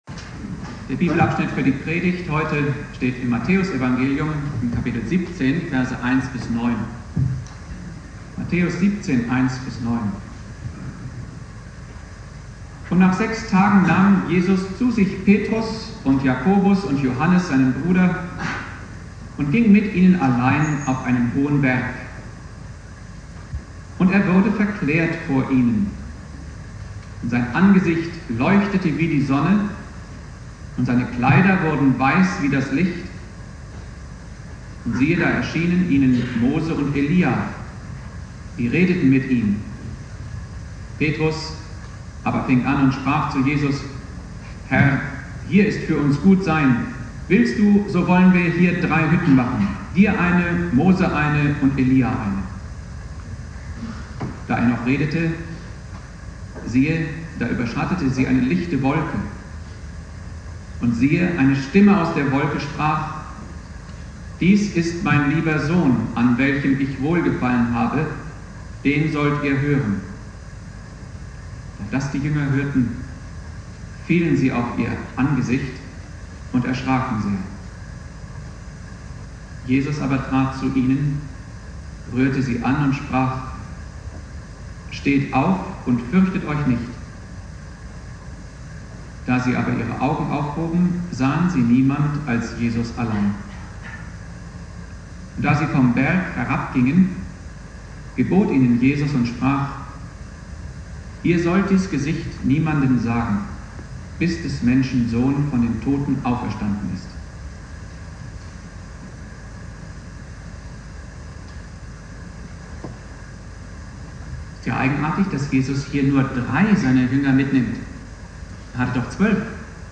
Predigt
Thema: "Verklärung Jesu" (mit Außenmikrofon aufgenommen) Bibeltext